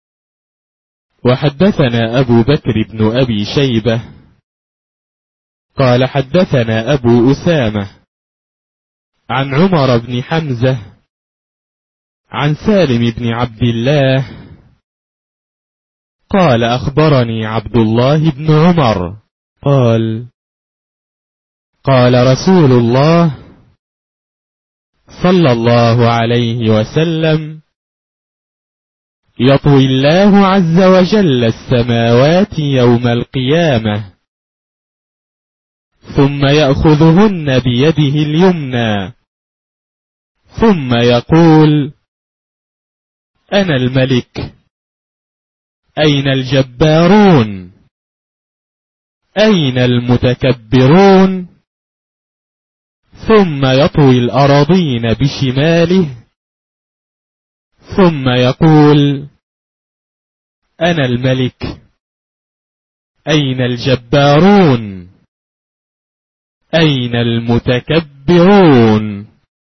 3. الـكتب الناطقة باللغة العربية